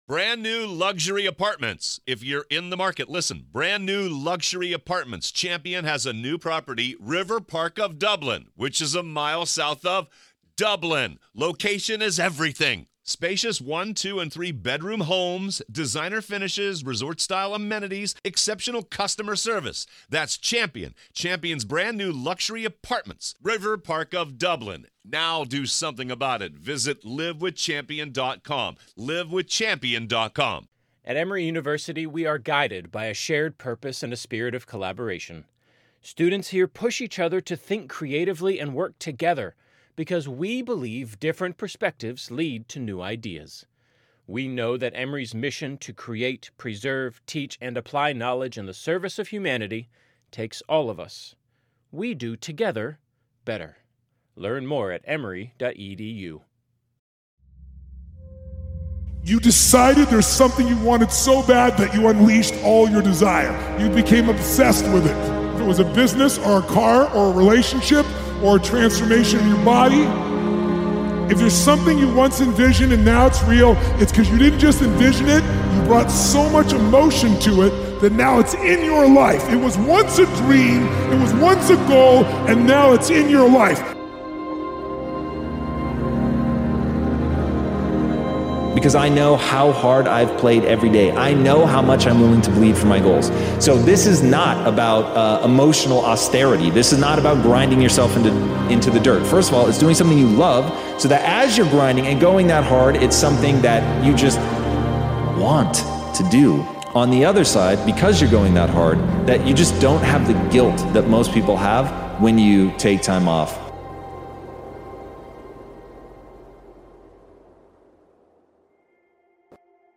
Speakers: Tom Bilyeu Tony Robbins Wayne Dyer